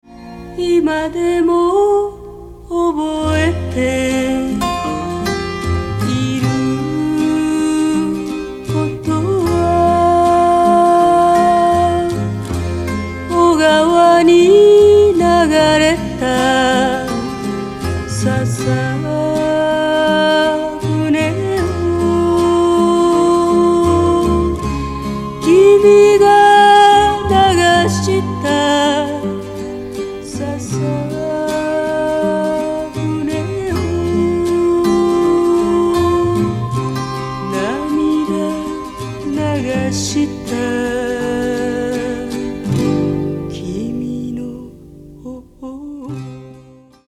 ジャンル：フォーク